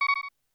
low_hp.wav